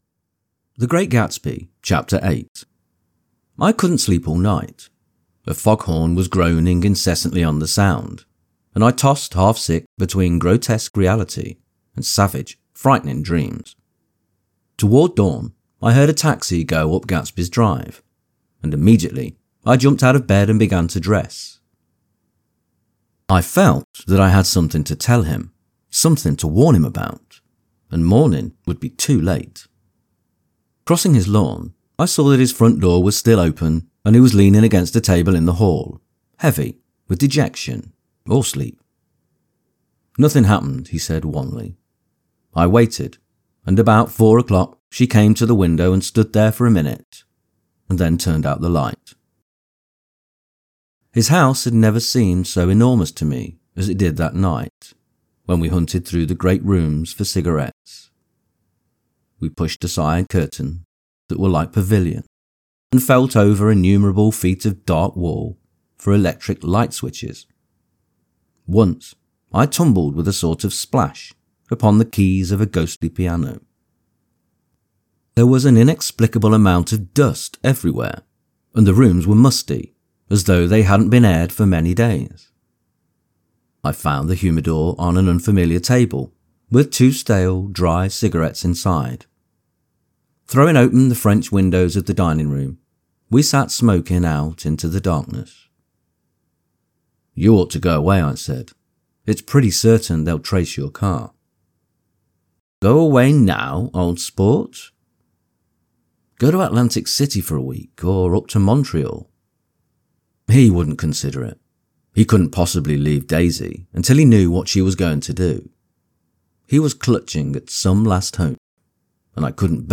The Great Gatsby Audio-book – Chapter 8 | Soft Spoken English Male Full Reading (F.Scott Fitzgerald) - Dynamic Daydreaming